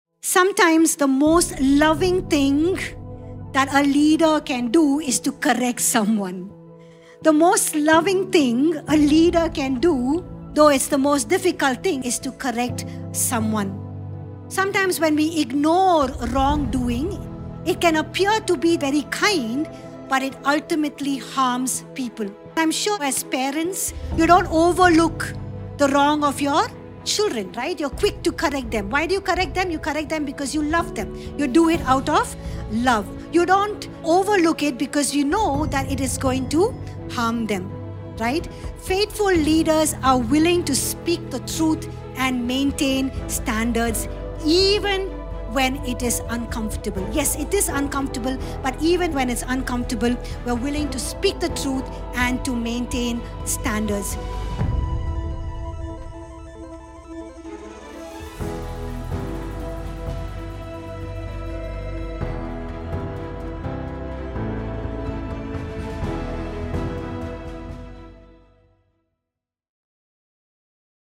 Sermon Keypoints Podcast - Proverbs on Leadership - Proverbs for Daily Living | Free Listening on Podbean App